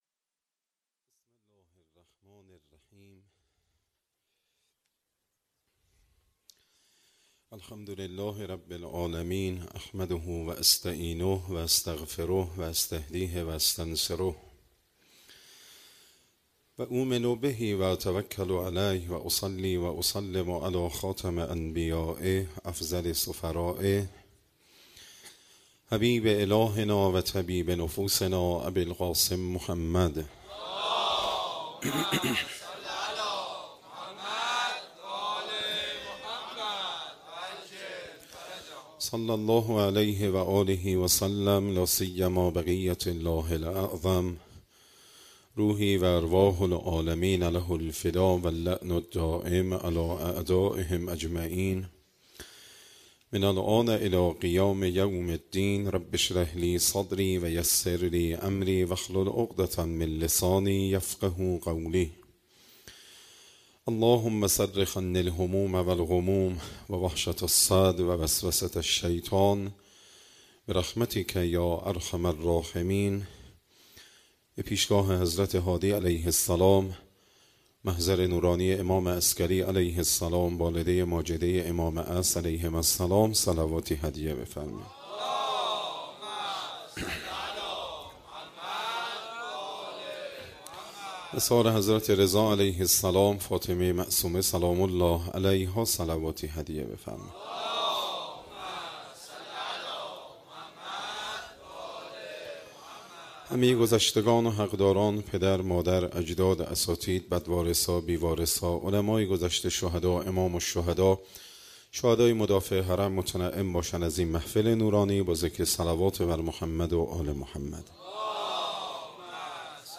شب 6 ماه رمضان - هیئت مکتب الزهرا سلام الله علیها